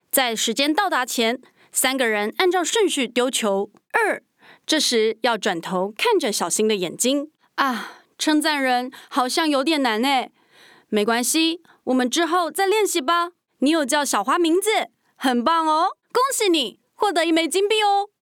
台湾普通话
旁白解说